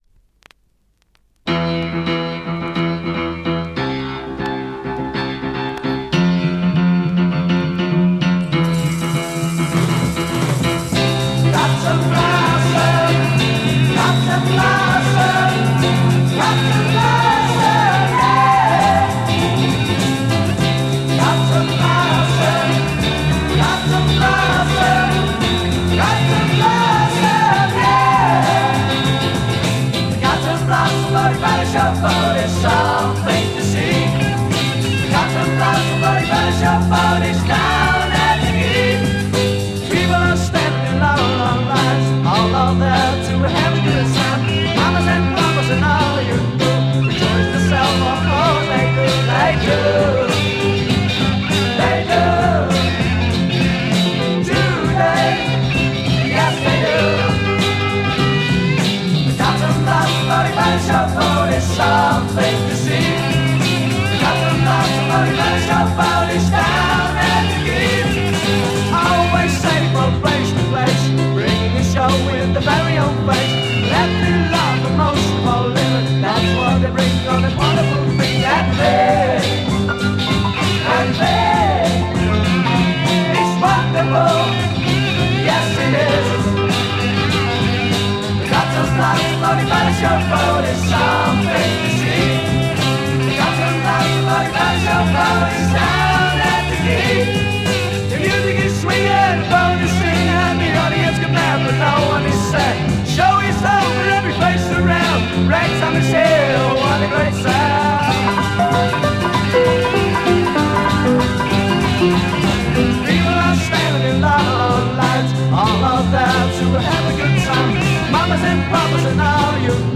Dutch Freakbeat Mod pop-sike
Magic freakbeat pop-sike single in fantastic condition!!!